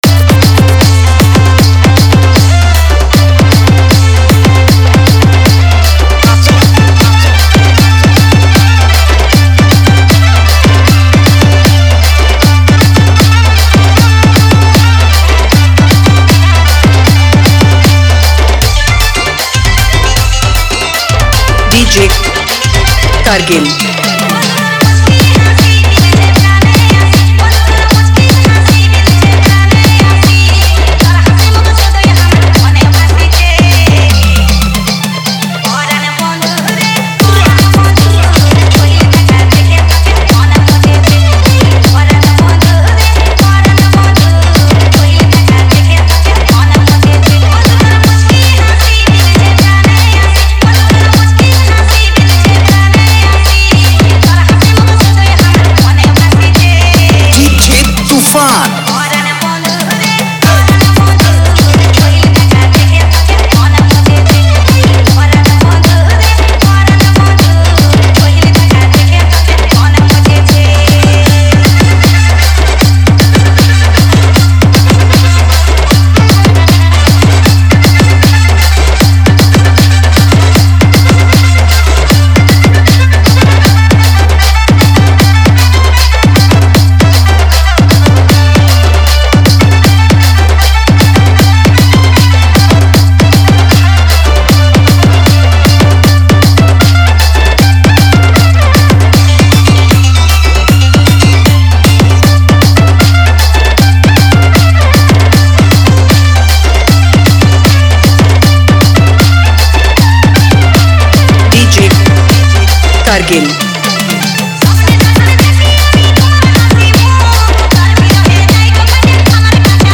Humming Dance Mix 2021
Category: Odia Gaana Sambalpuri Viral Cg Dj Mix Song 2021